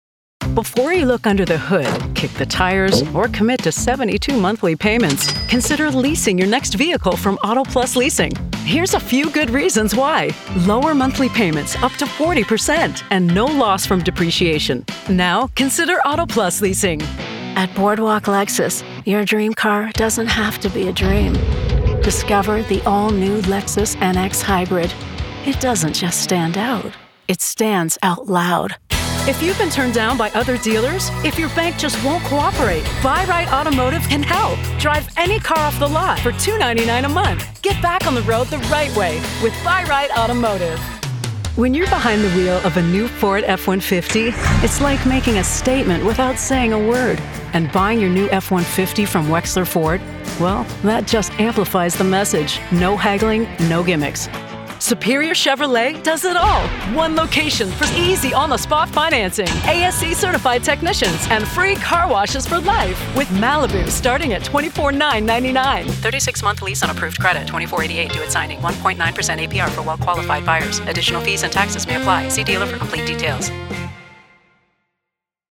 Female Voice Over, Dan Wachs Talent Agency.
Warm, sassy, gritty, real, mature.
Automotive